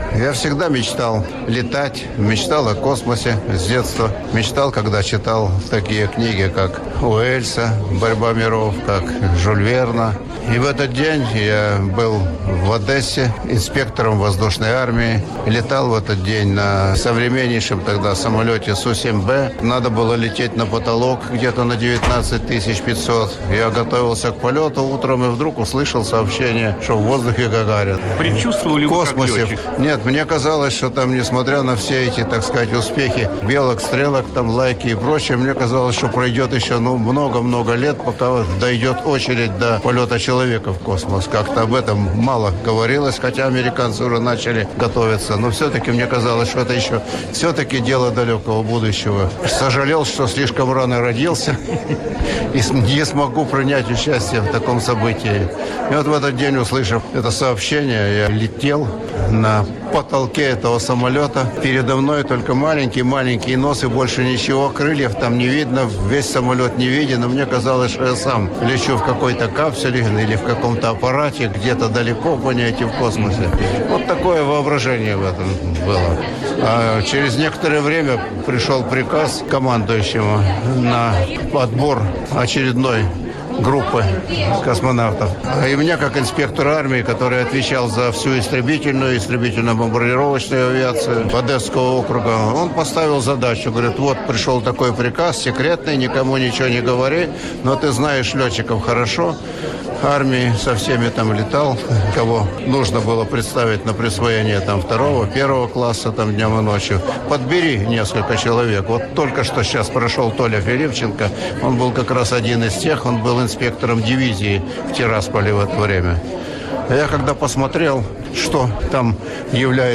Раздел 4: Фрагменты эксклюзивных интервью космонавтов | Центр Авангард
kosmonavt_v_shatalov_2.wav